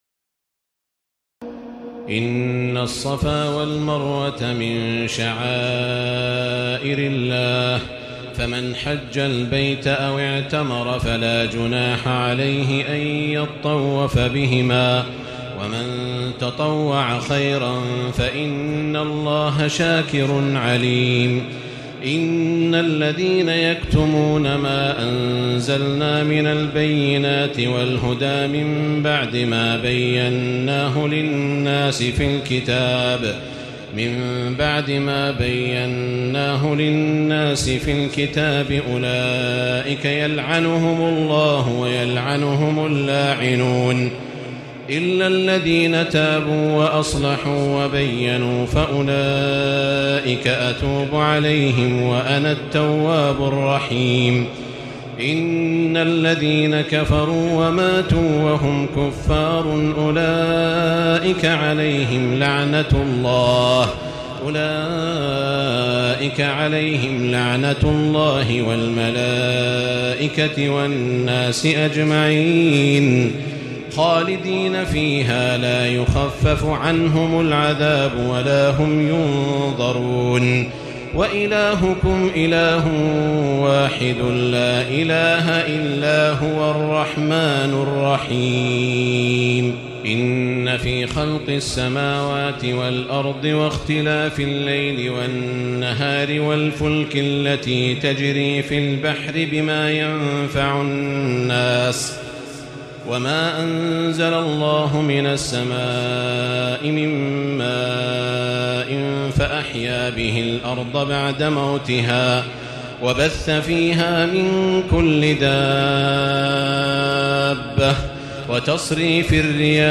تراويح الليلة الثانية رمضان 1436هـ من سورة البقرة (158-227) Taraweeh 2 st night Ramadan 1436 H from Surah Al-Baqara > تراويح الحرم المكي عام 1436 🕋 > التراويح - تلاوات الحرمين